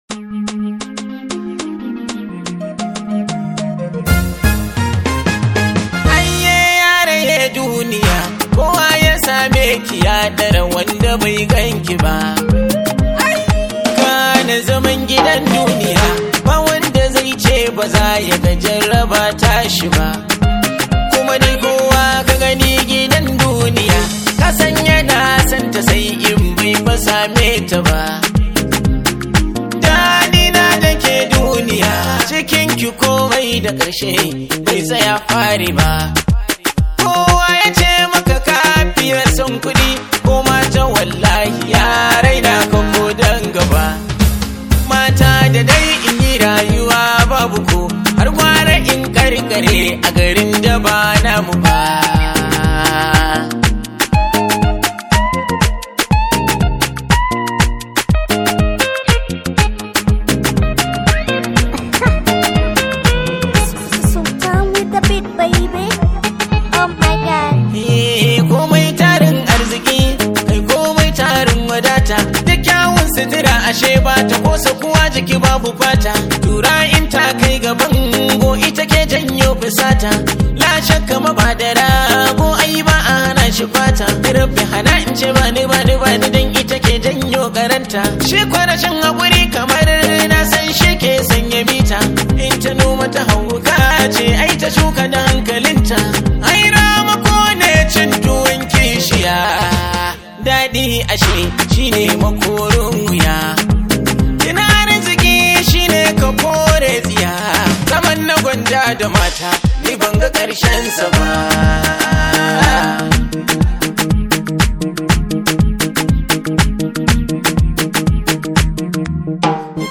Hausa Songs